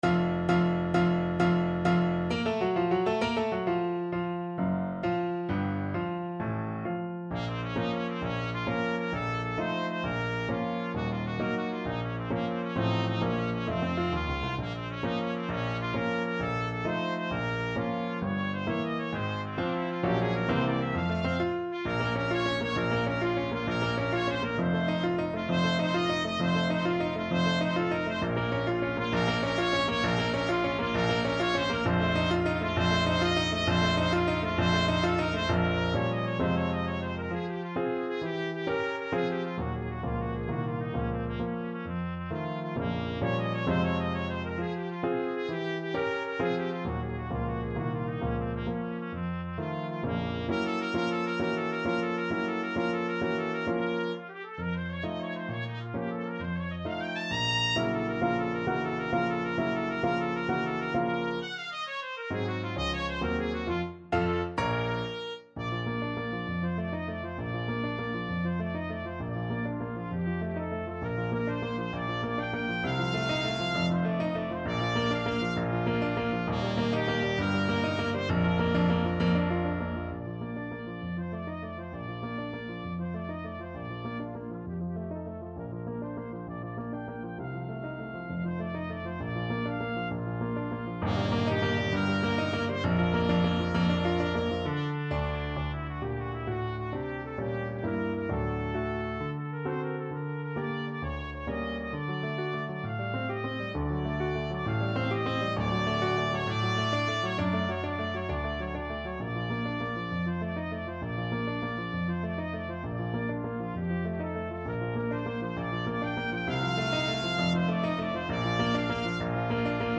Free Sheet music for Trumpet
Bb minor (Sounding Pitch) C minor (Trumpet in Bb) (View more Bb minor Music for Trumpet )
6/8 (View more 6/8 Music)
~. = 132 Allegro con spirito (View more music marked Allegro)
Classical (View more Classical Trumpet Music)